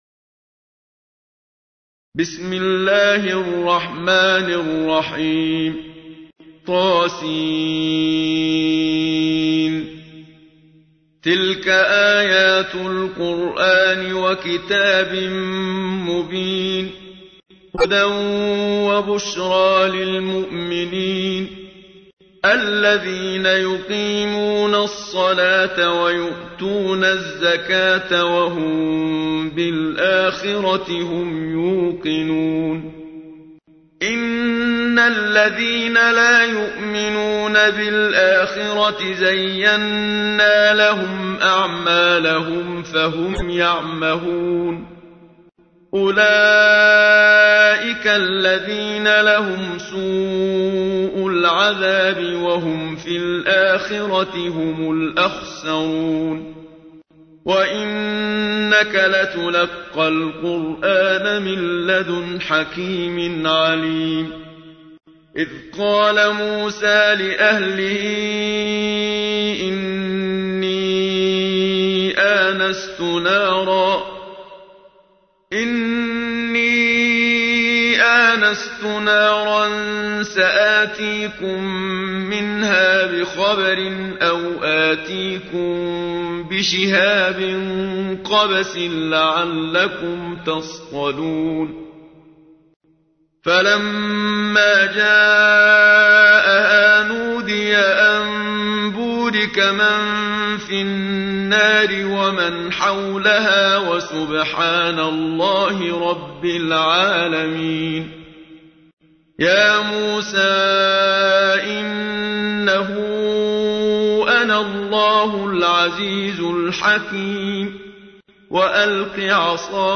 تحميل : 27. سورة النمل / القارئ محمد صديق المنشاوي / القرآن الكريم / موقع يا حسين